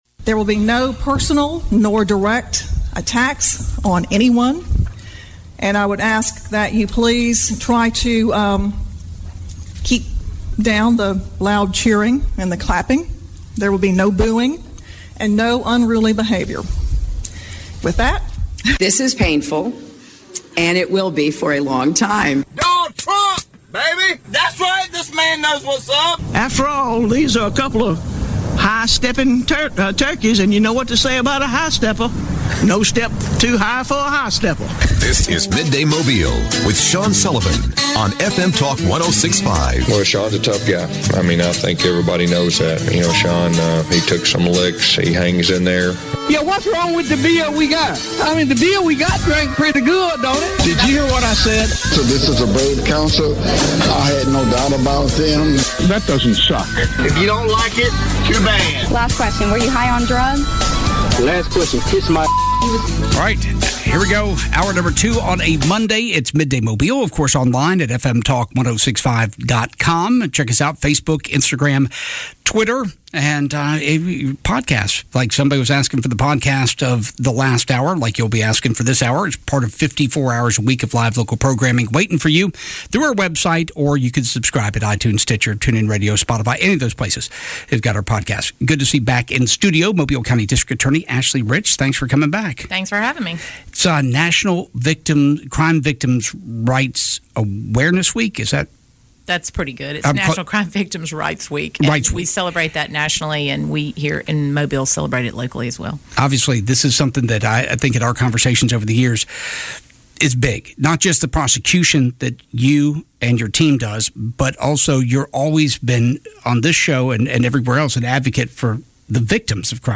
Guests were Mobile DA Ashley Rich and Congressman Jerry Carl